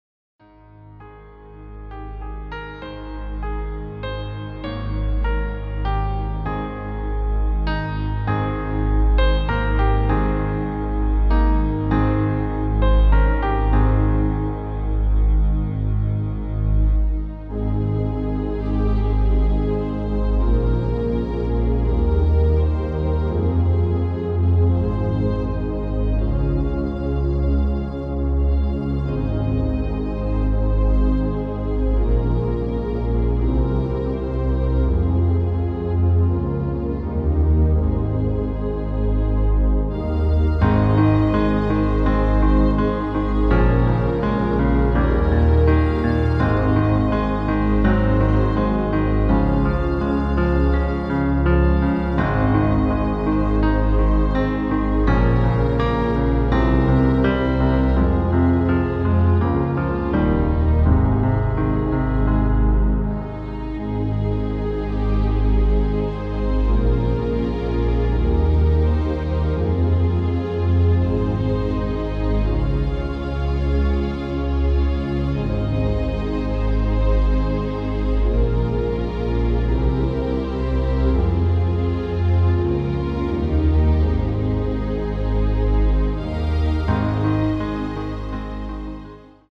• Das Instrumental beinhaltet NICHT die Leadstimme
• Vorsicht: 3/4 Takt Vorspiel
• Break und Wechsel auf 4/4 Takt
• 3x Strophe + Refrain
• Break & Tonerhöhung